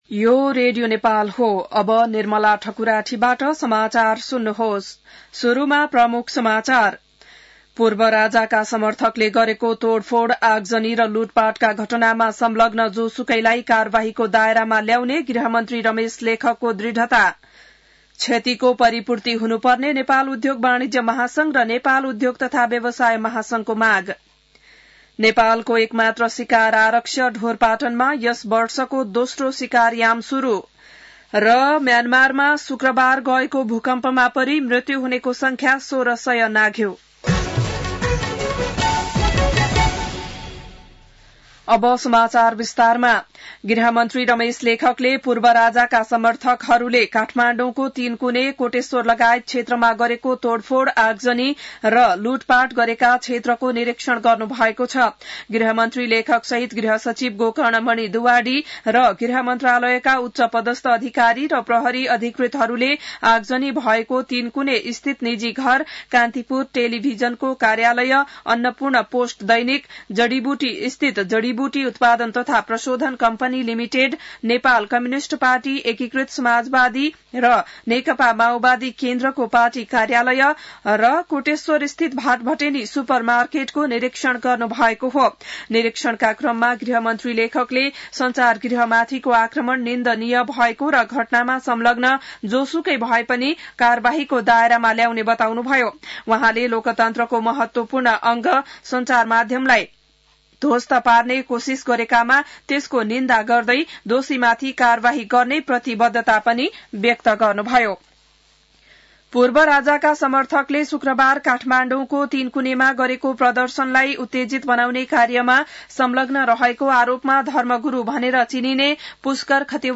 बिहान ९ बजेको नेपाली समाचार : १७ चैत , २०८१